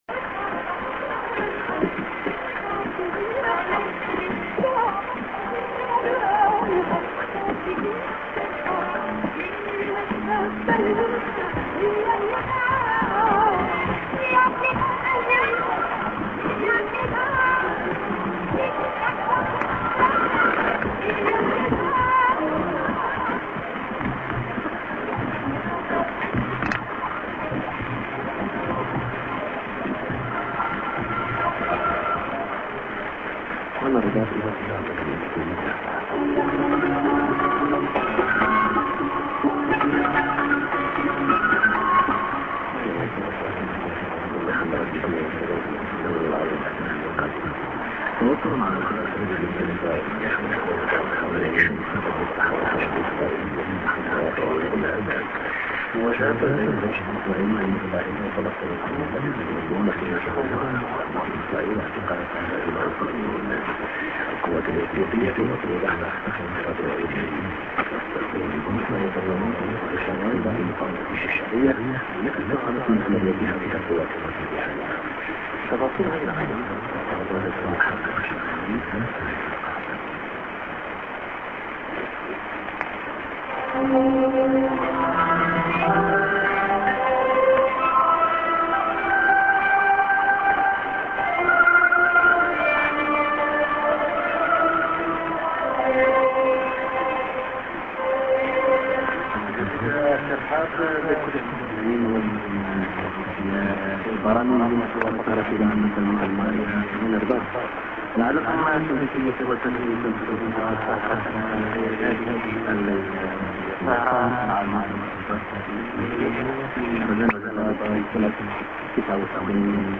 ->music->ANN(men)->SJ->ANN(man)->　＊07:00 //15,335 time